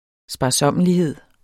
Udtale [ sbɑˈsʌmˀəliˌheðˀ ]